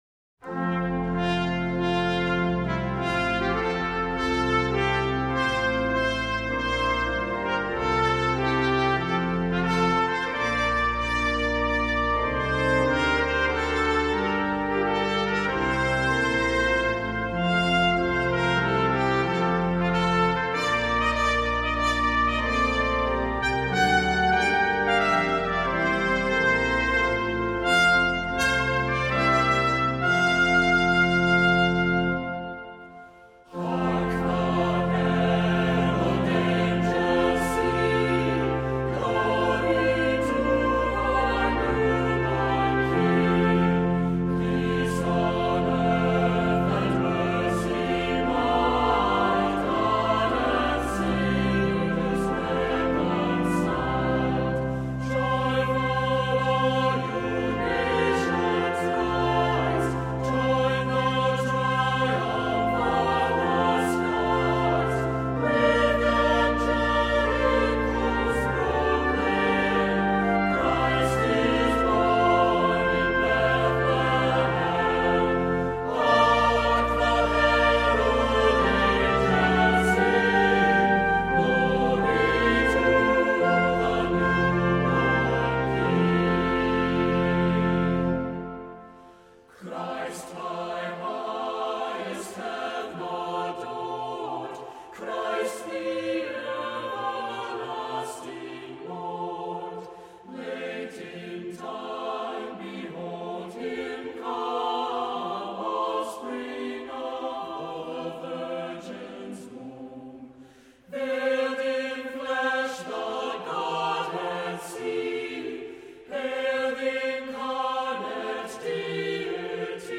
Accompaniment:      Organ
Music Category:      Choral